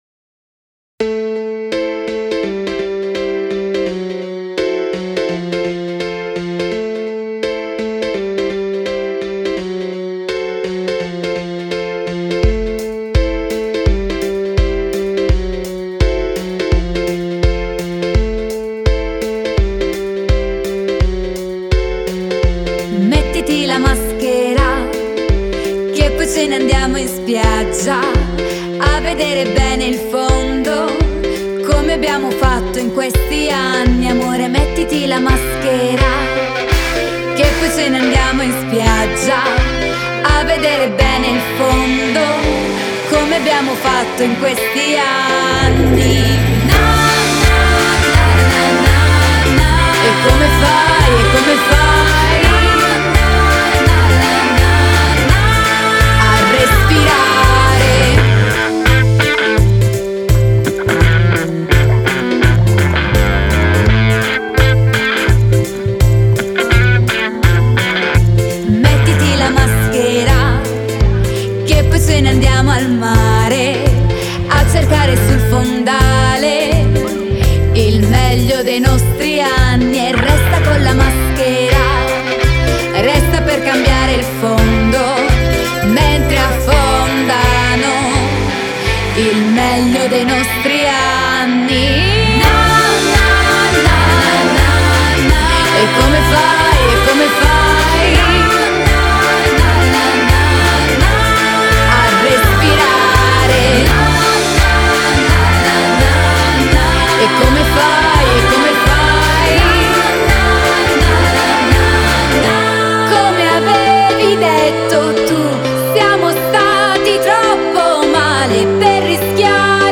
Genre: Pop Rock, Indie